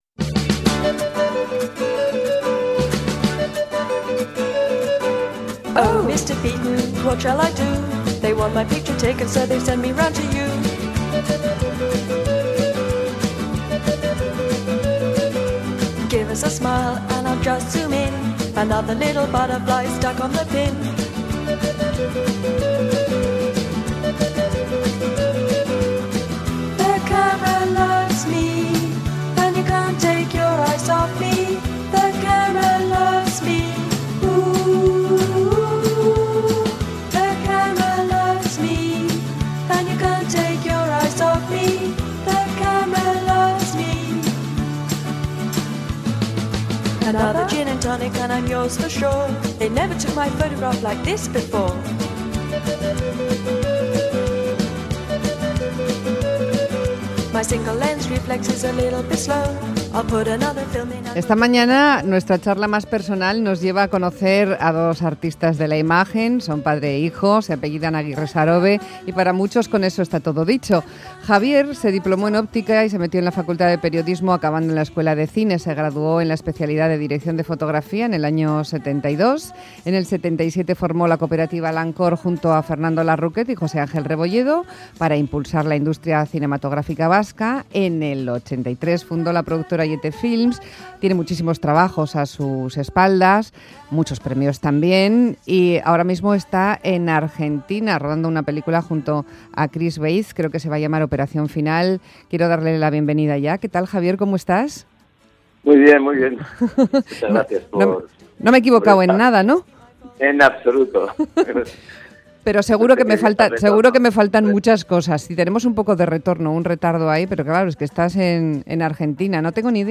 entrevista en Radio Euskadi.